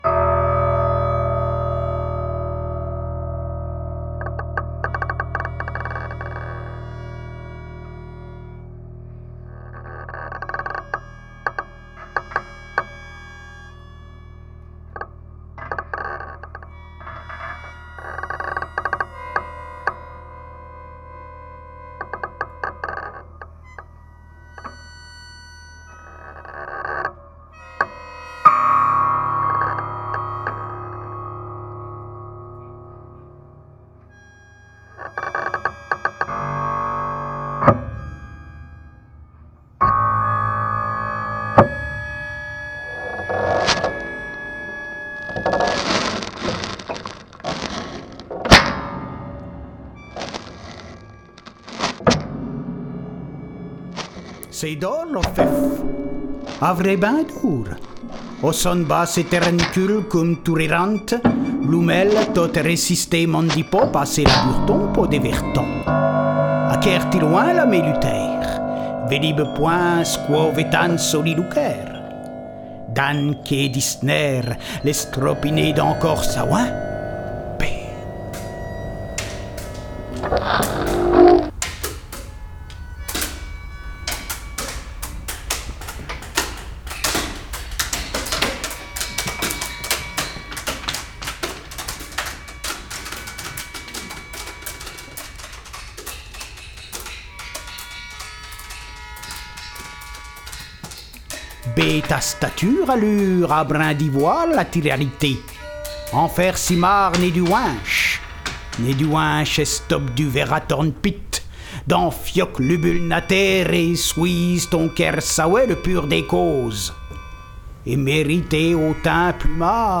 Dans la lignée du zaoum de Velimir Khlebnikov et des avant-gardes de la musique concrète